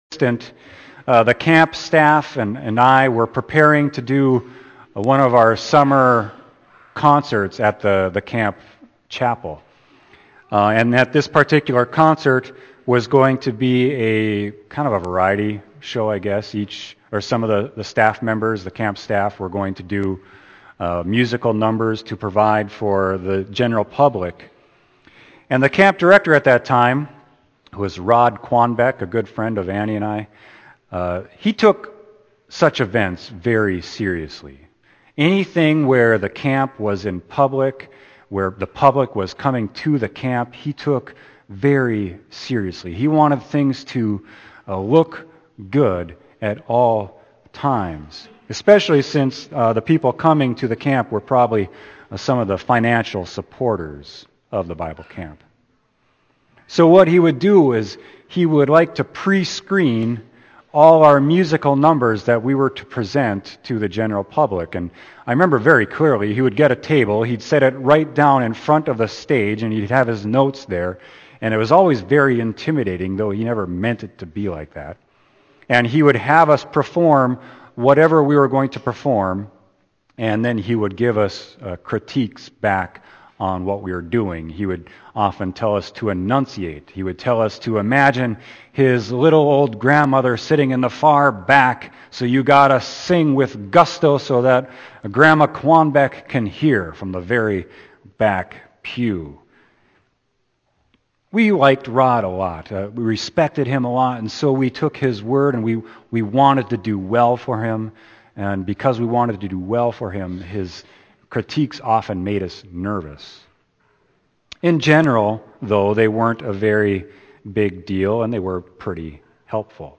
Sermon: Luke 13.1-9